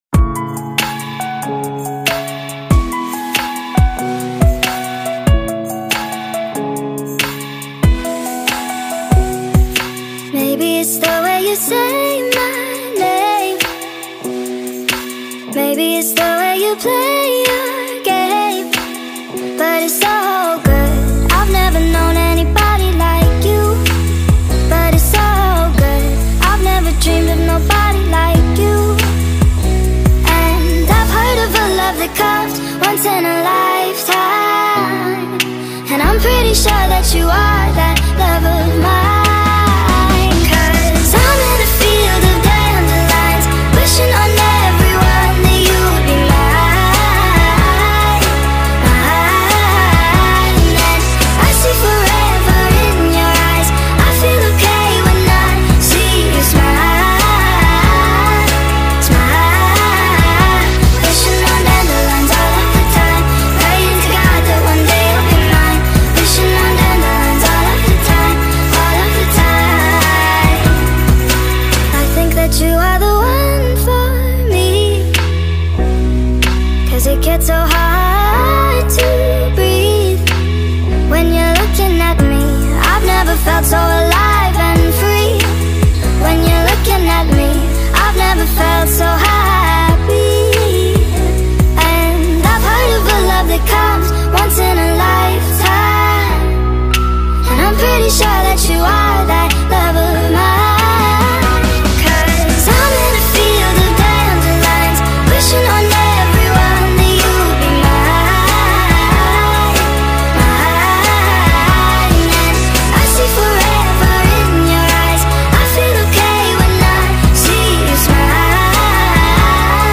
نسخه سریع شده و Sped Up
عاشقانه خارجی